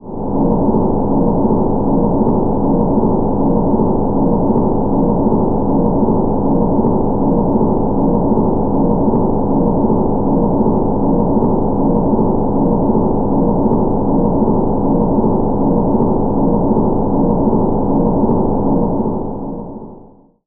underwater-sound